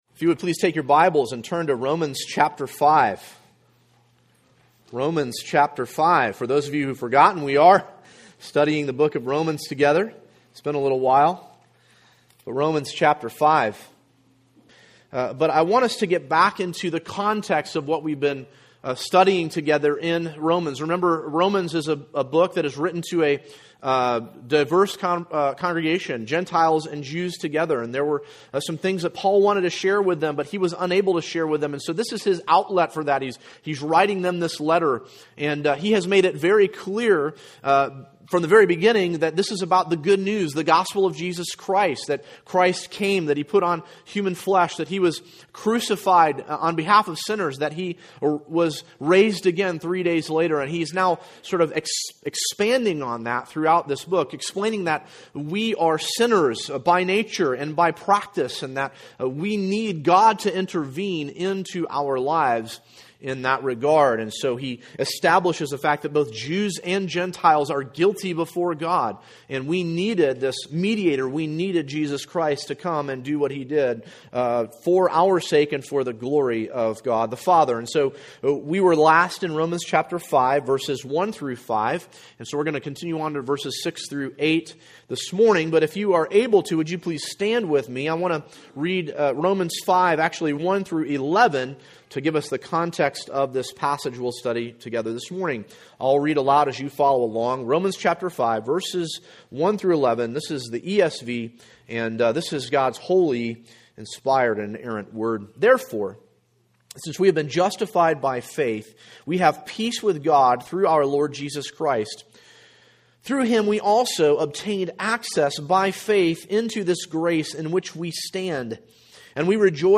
An exposition